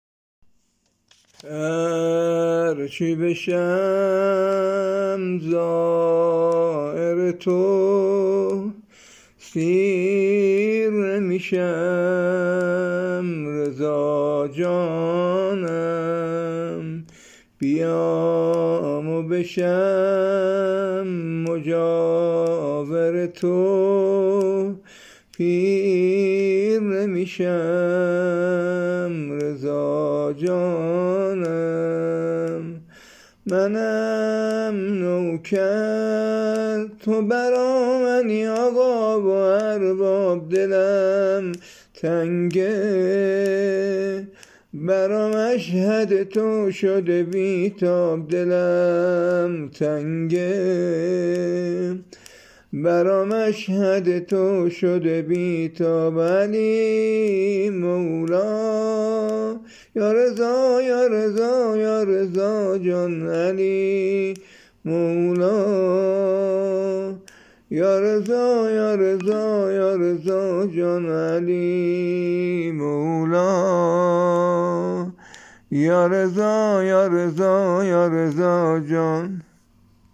زمزمه وزمینه شهادت امام رضا(ع)